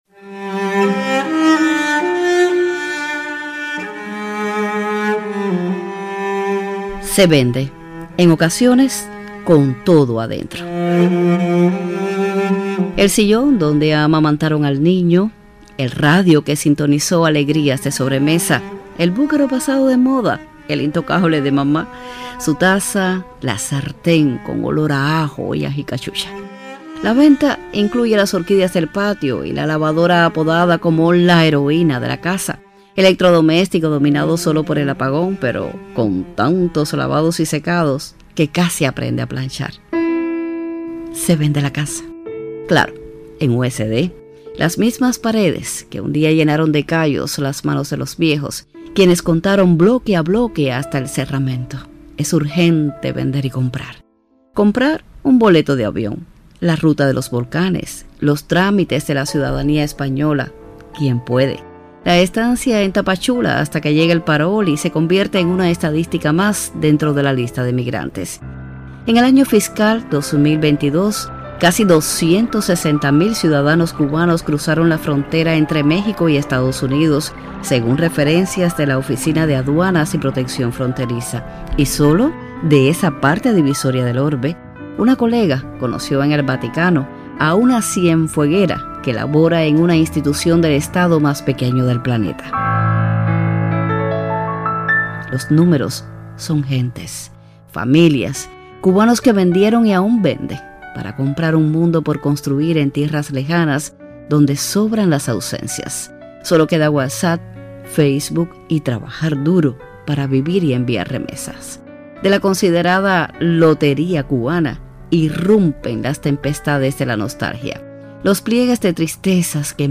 En la categoría de géneros informativos la crónica “Se vende”